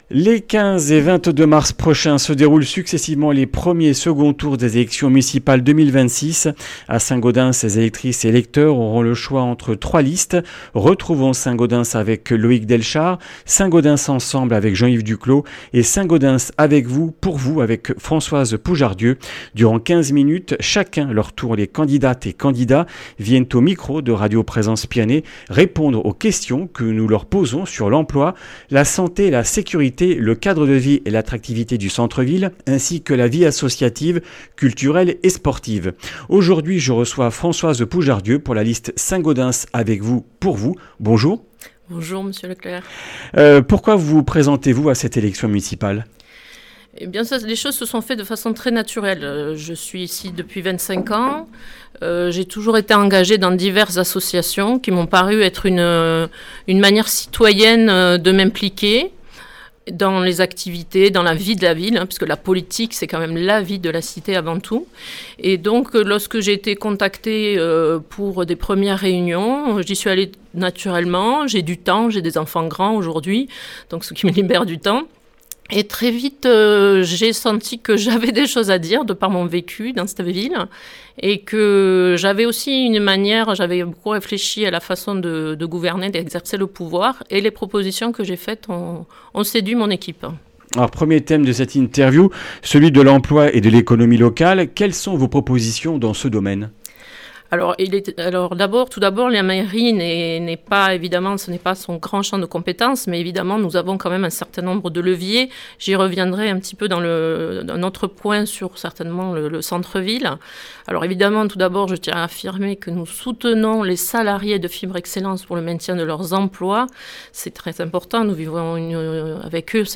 Durant 15 minutes, chacun leur tour, les candidates et candidats viennent au micro de Radio Présence Pyrénées répondre aux questions que nous leur posons sur l’emploi, la santé, la sécurité, le cadre de vie et l’attractivité du centre-ville, ainsi que la vie associative, culturelle et sportive. Interview